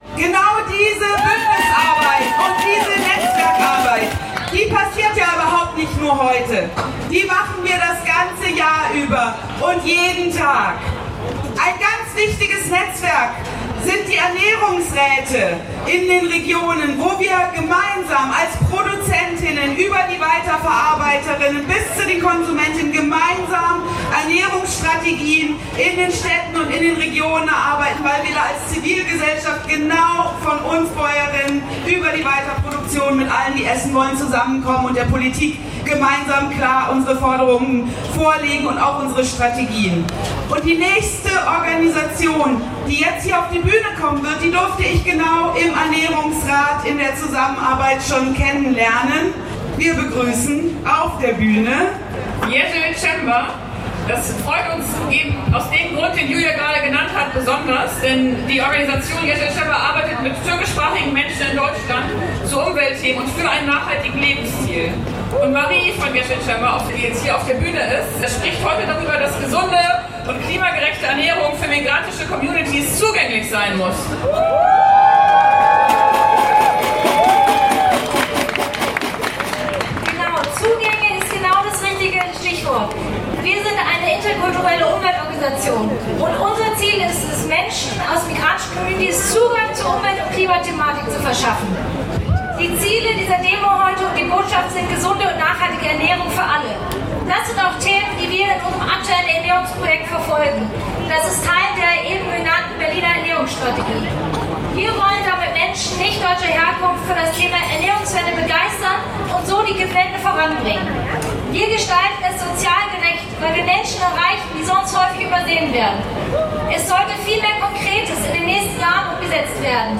Abschlusskundgebung
Der zweite Teil des Bühnenprogramms